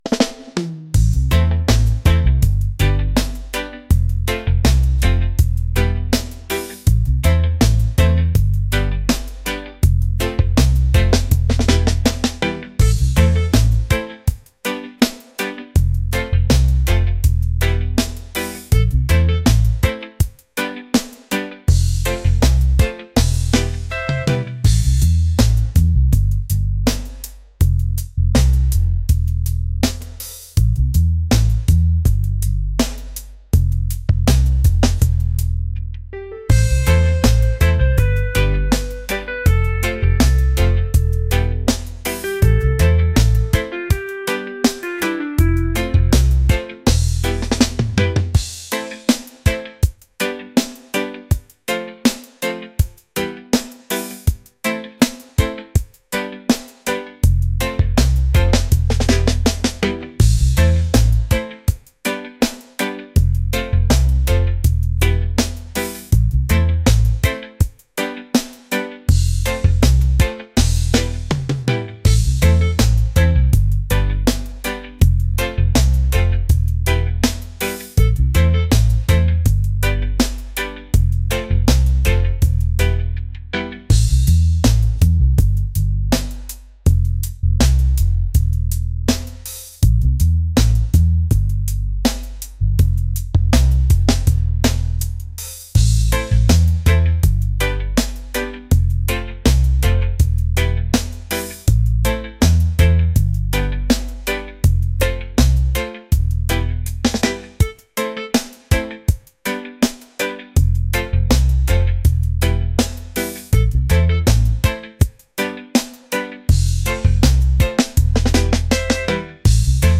relaxed | laid-back | reggae